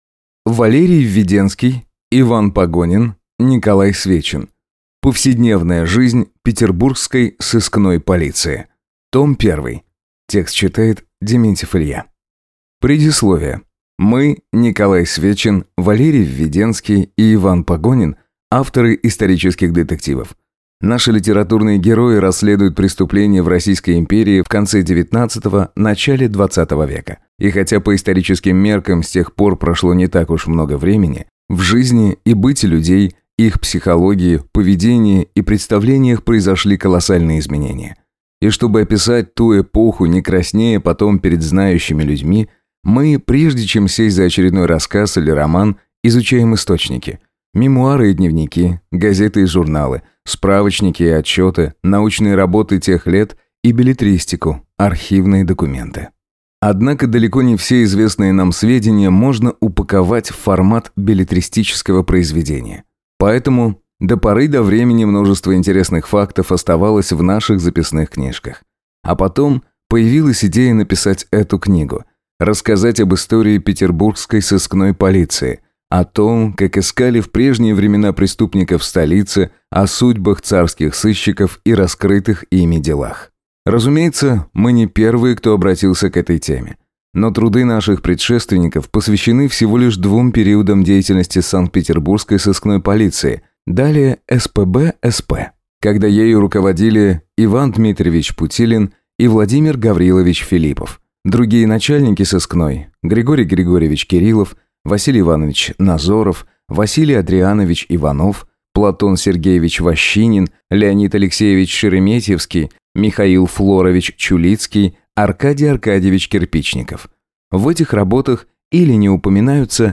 Аудиокнига Повседневная жизнь петербургской сыскной полиции | Библиотека аудиокниг